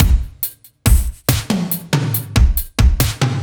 Index of /musicradar/french-house-chillout-samples/140bpm/Beats